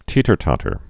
(tētər-tŏtər)